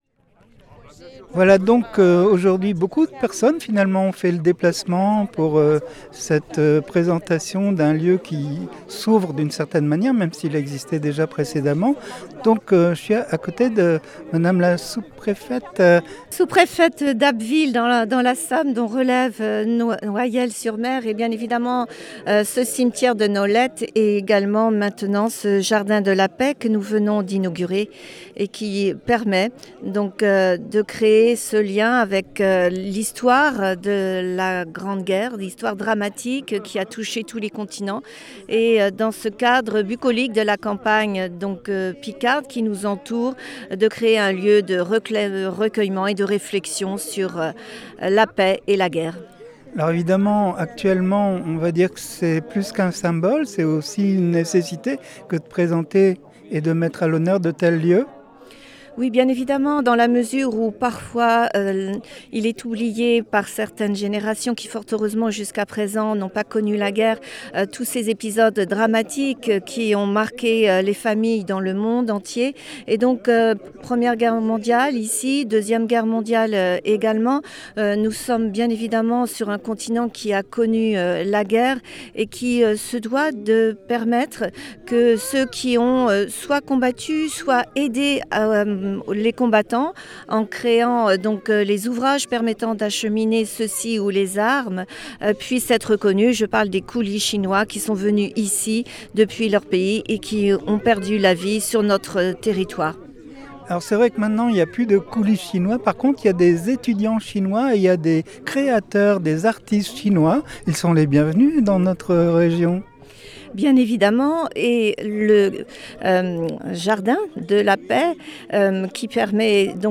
un reportage en images et en sons
sous-préfète d’Abbeville